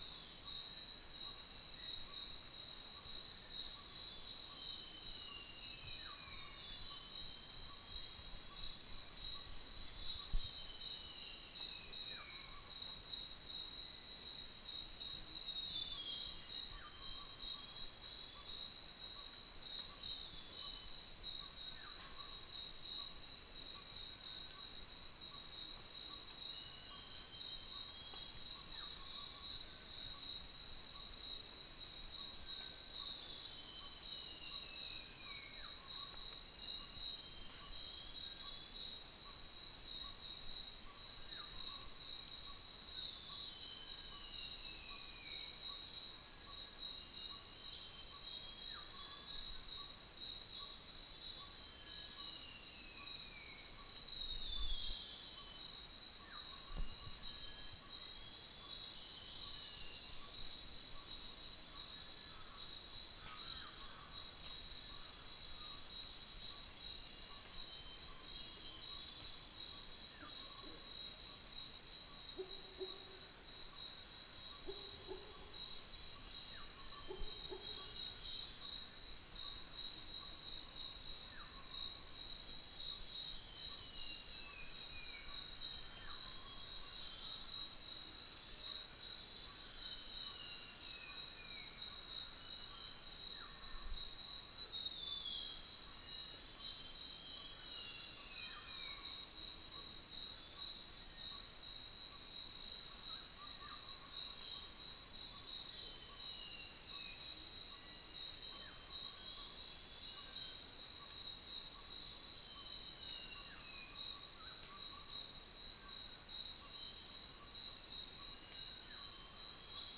Upland plots dry season 2013
Stachyris maculata
Pomatorhinus montanus
Trichixos pyrropygus